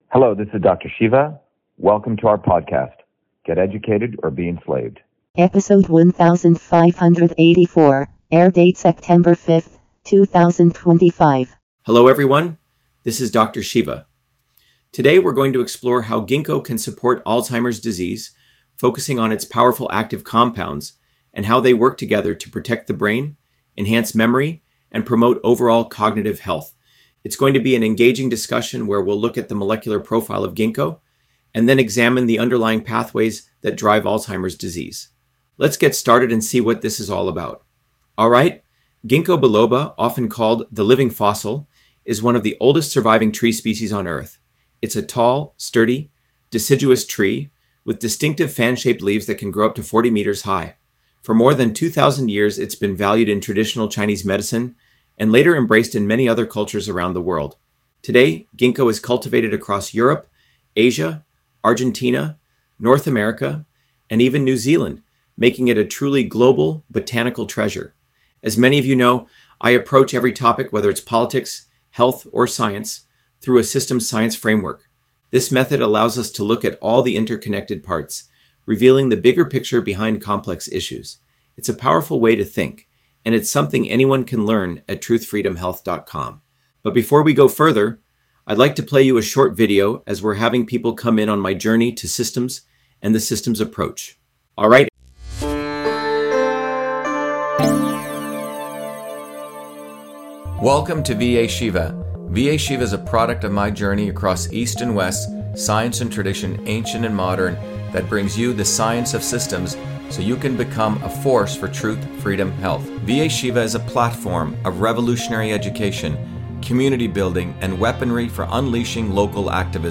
In this interview, Dr.SHIVA Ayyadurai, MIT PhD, Inventor of Email, Scientist, Engineer and Candidate for President, Talks about Ginkgo biloba on Alzheimer’s Disease: A Whole Systems Approach